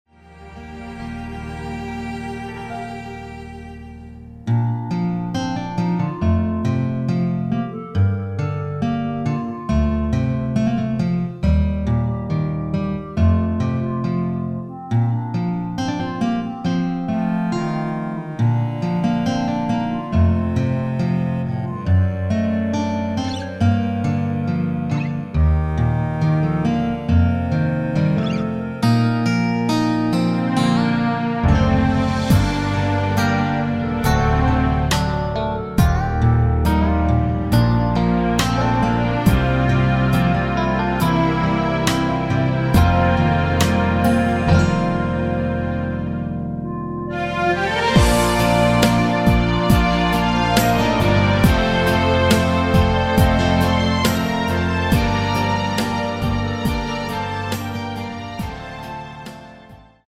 Bb
노래방에서 노래를 부르실때 노래 부분에 가이드 멜로디가 따라 나와서
앞부분30초, 뒷부분30초씩 편집해서 올려 드리고 있습니다.
중간에 음이 끈어지고 다시 나오는 이유는